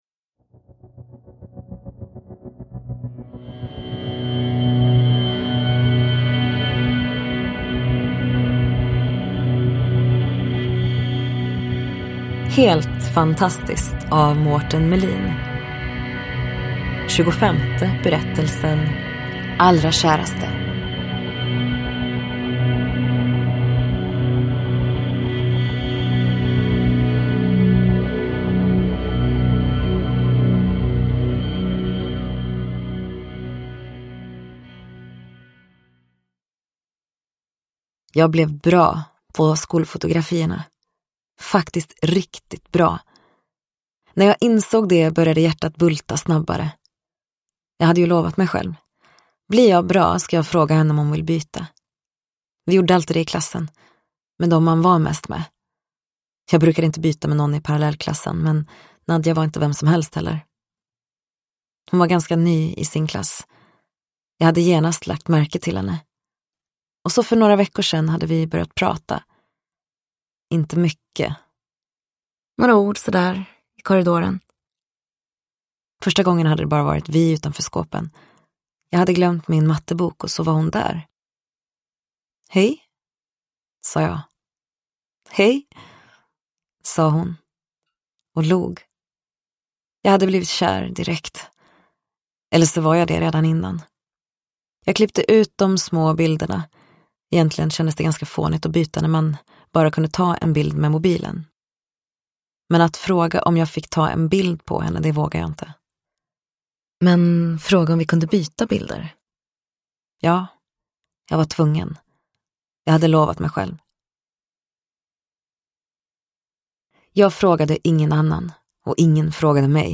Allrakäraste : en novell ur samlingen Helt fantastiskt – Ljudbok – Laddas ner